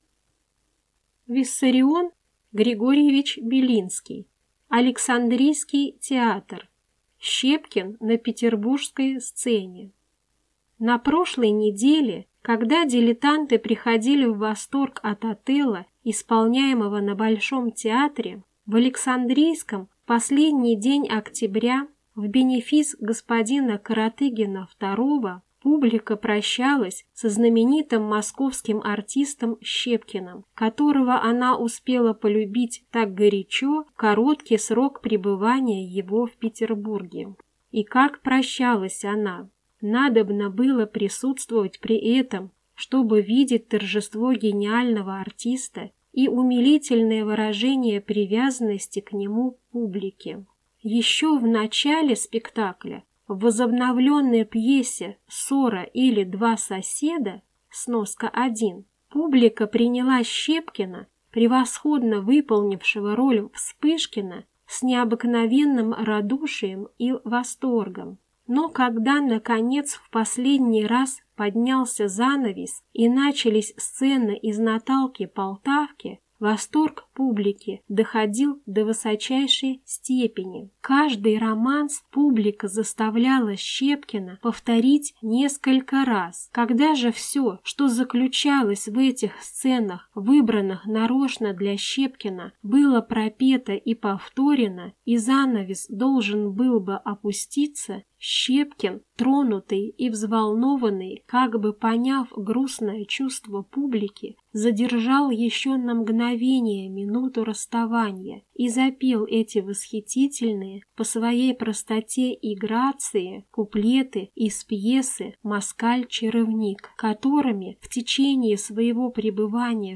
Аудиокнига Александрийский театр. Щепкин на петербургской сцене | Библиотека аудиокниг